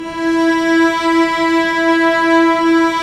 Index of /90_sSampleCDs/Roland L-CD702/VOL-1/STR_Vcs Bow FX/STR_Vcs Sordino